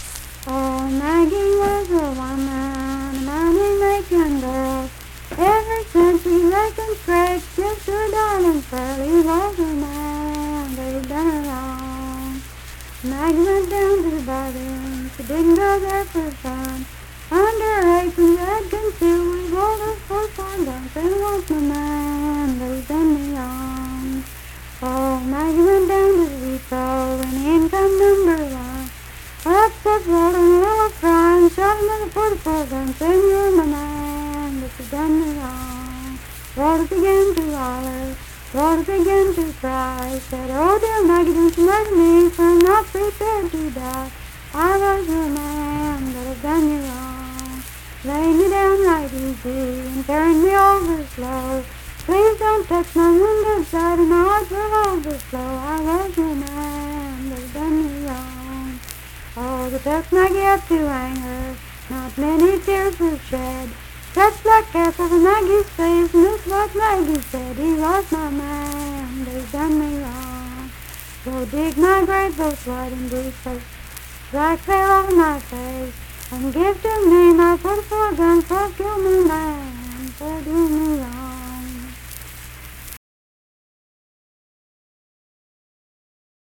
Unaccompanied vocal music
Verse-refrain 7d(3w/R).
Voice (sung)
Sutton (W. Va.), Braxton County (W. Va.)